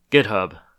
Summary Description En-us-GitHub.ogg English: Audio pronunciation of the name "GitHub" in U.S. English (Midwestern accent).
En-us-GitHub.ogg